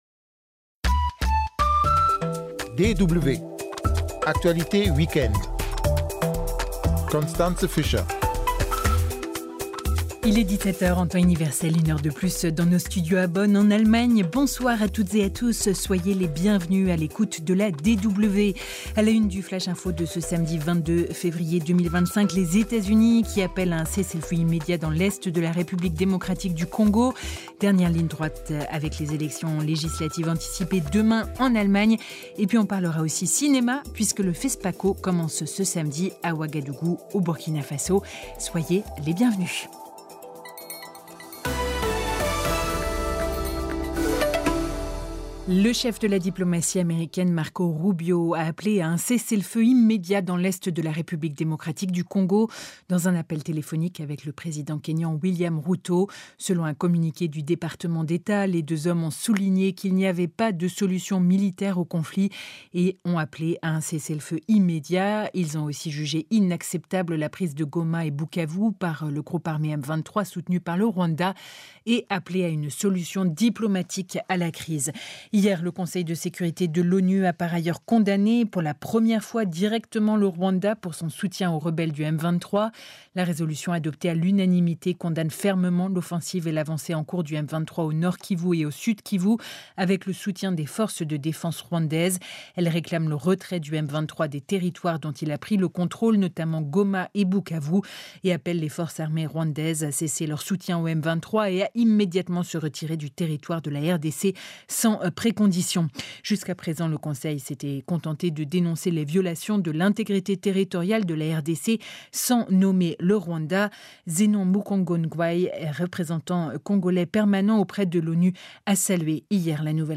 Journal d'informations internationales et africaines.